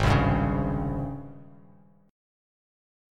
G#M11 chord